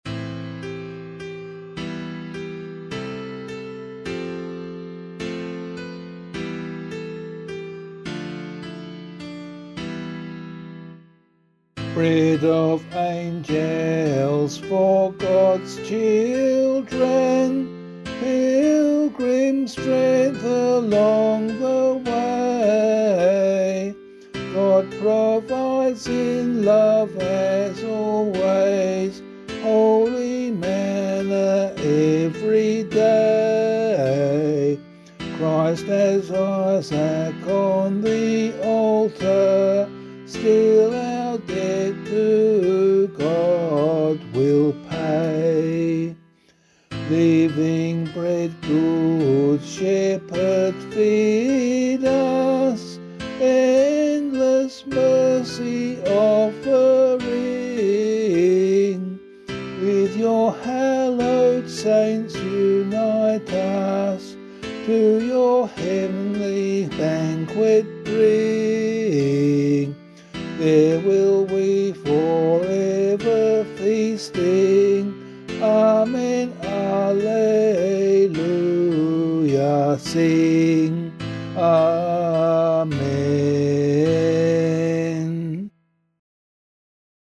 Composer:    Chant, mode III
vocal